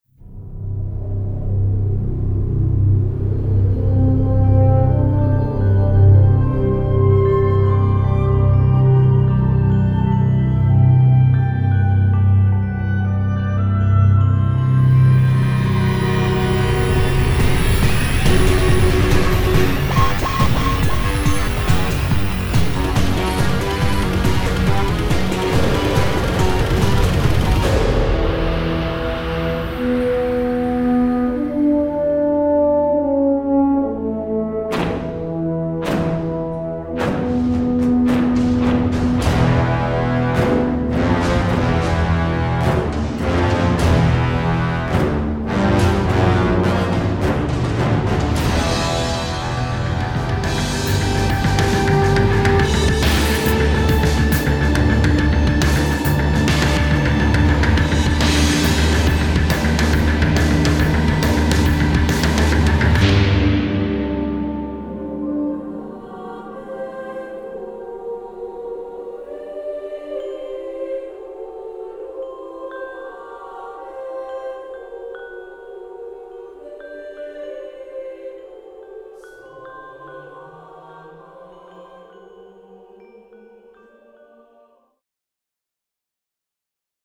They also compiled a heroic medley to whet your appetite.